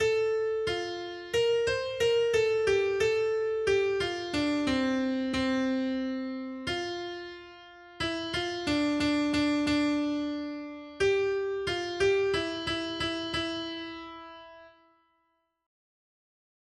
Noty Štítky, zpěvníky ol340.pdf responsoriální žalm Žaltář (Olejník) 340 Skrýt akordy R: Smím si vykračovat před Pánem v zemi živých. 1.